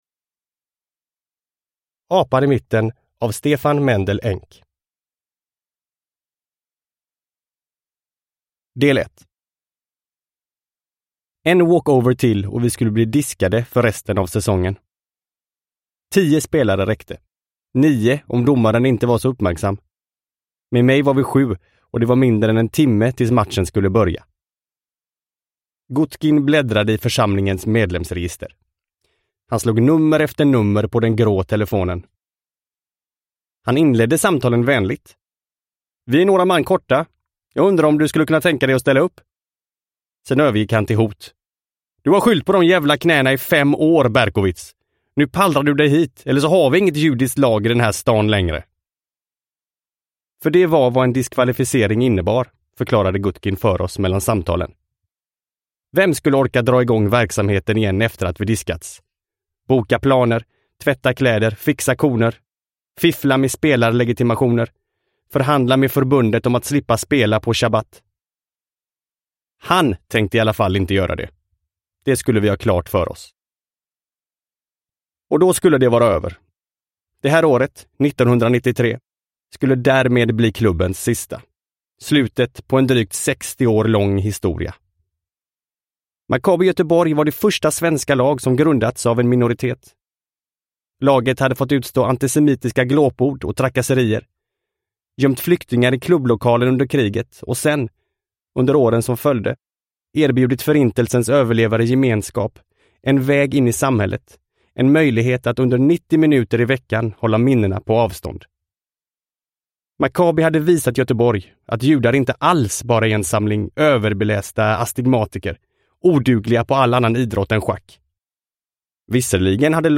Apan i mitten – Ljudbok – Laddas ner